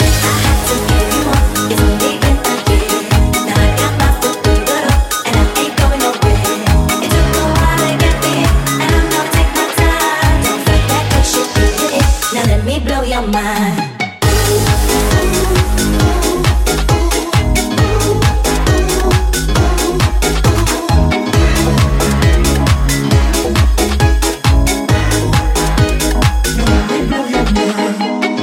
Genere: pop, deep, club, remix